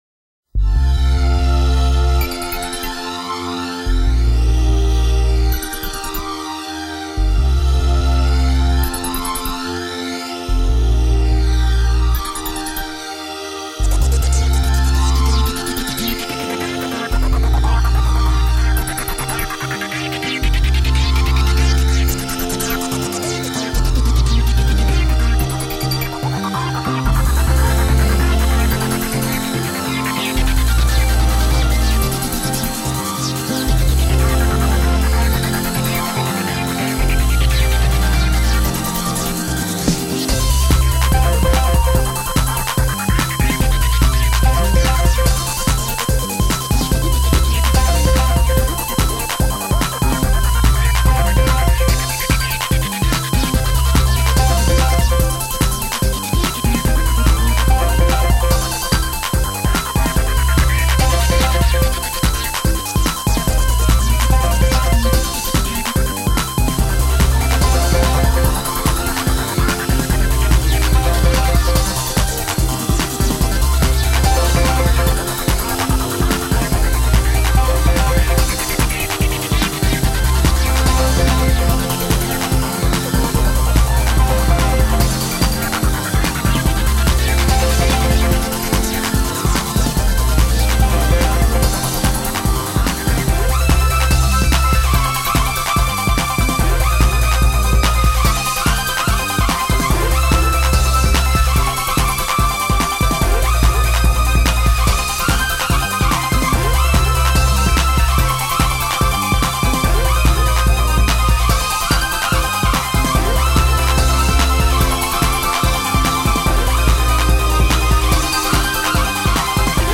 en plus pechu... et 'ZE HIGH SCORE Touch' à la minute 1:35